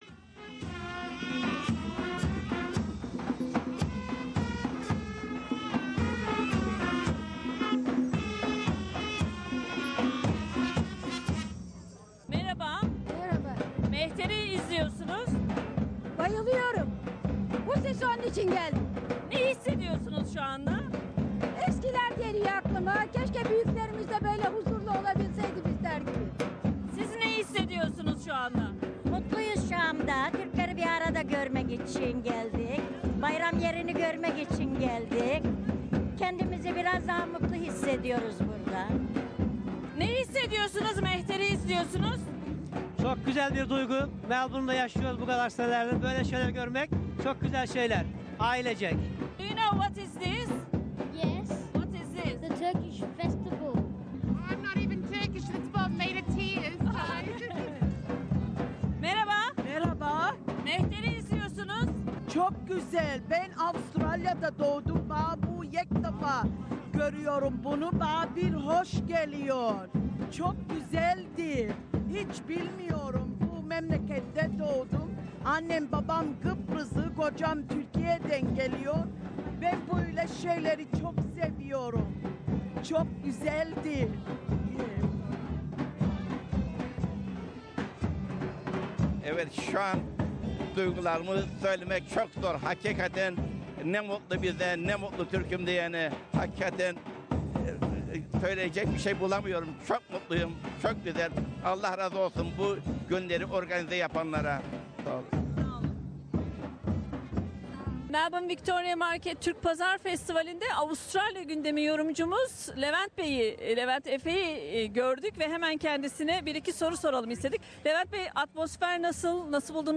11 Mart Cumartesi ve 12 Mart Pazar günleri Melbourne'da, Queen Victoria Market'te gerçekleştirilen ve Moreland Türk Derneği tarafından düzenlenen 'Victoria Market Türk Pazar Festivali'nde SBS Türkçe Programı çalışanları da yer alarak sizlerle birlikte programlar, kayıtlar yaptı ve bunları hafta içindeki Türkçe yayınlarında sizlere sundu.